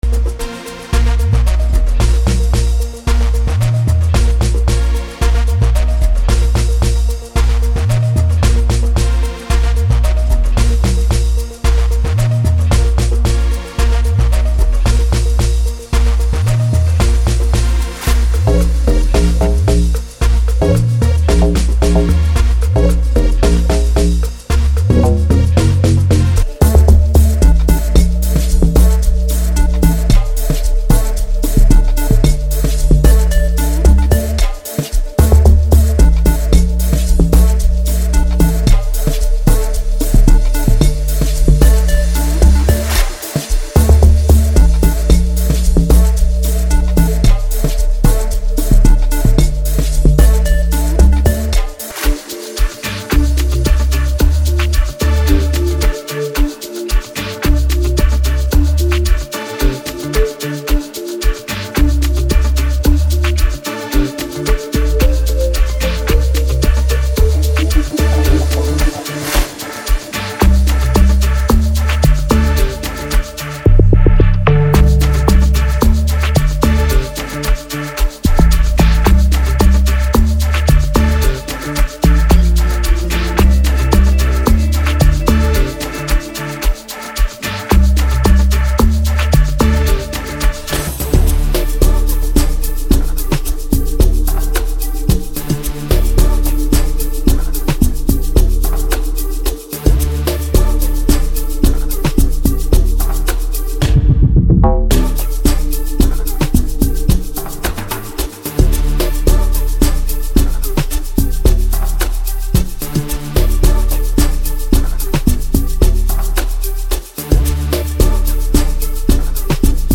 Amapiano Pack